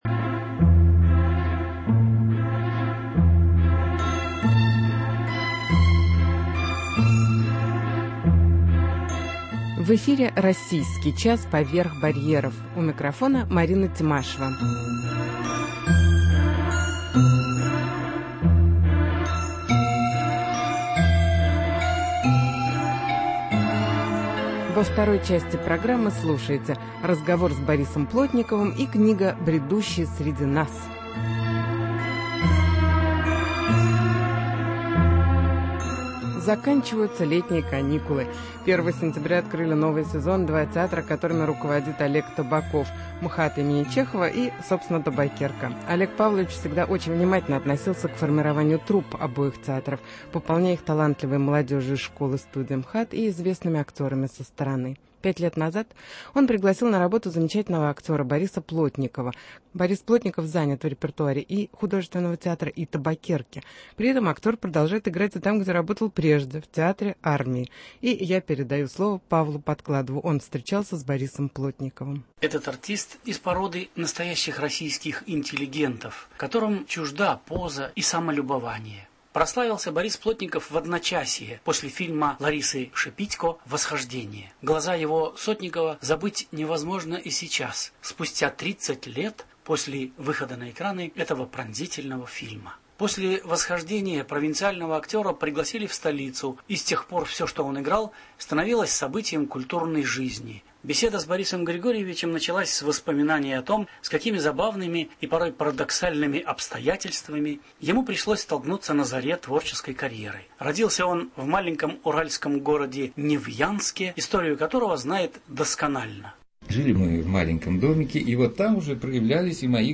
Интервью с артистом МХТ Борисом Плотниковым